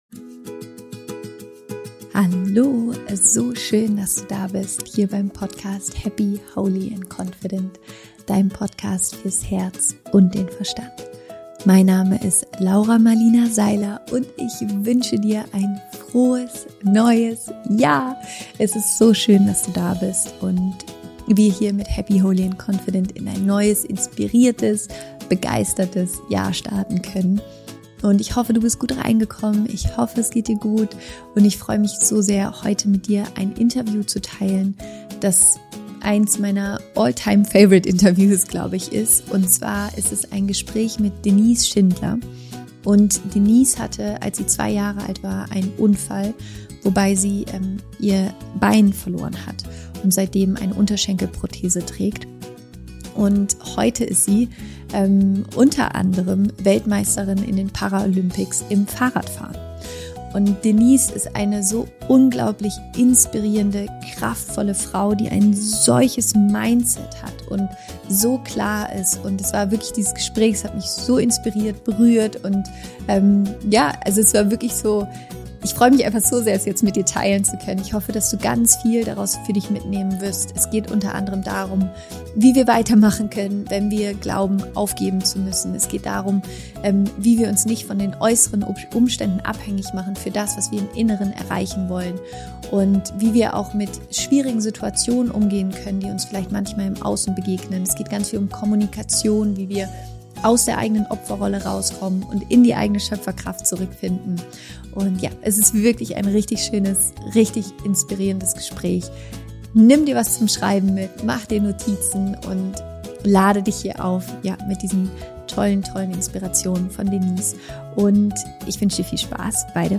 Wir starten 2022 mit einem Interview mit einer unfassbar inspirierenden und faszinierenden Frau: Denise Schindler!